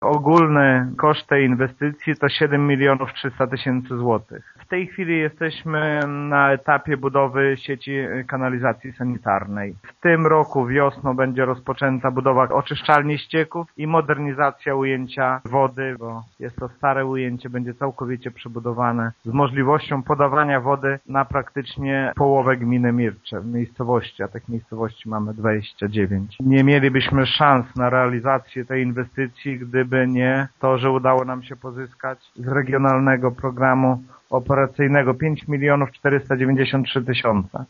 Największą tegoroczna inwestycją jest budowa kanalizacji sanitarnej i oczyszczalni ścieków oraz modernizacja ujęcia wody w Mirczu – mówi wójt Lech Szopiński: